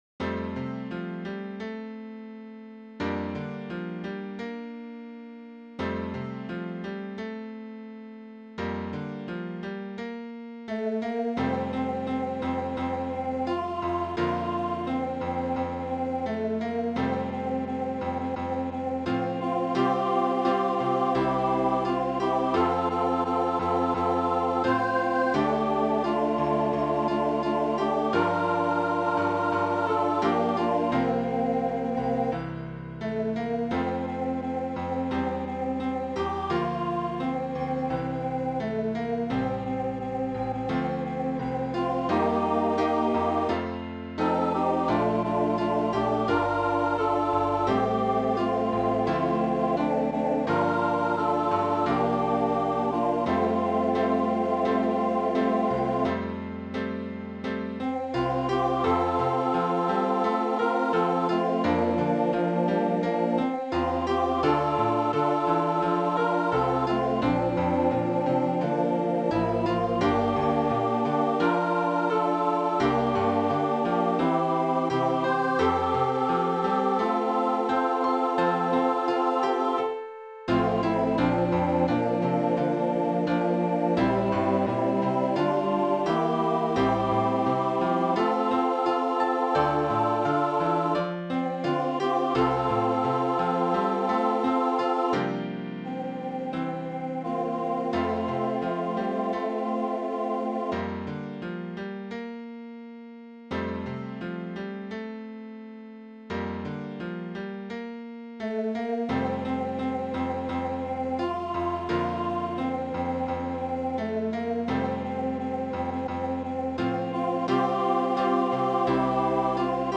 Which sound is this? Voicing/Instrumentation: SSA